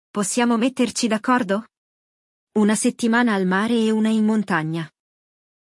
Nell’episodio di oggi ascolteremo la conversazione tra Luca e Cristina, una coppia che hanno gusti diversi.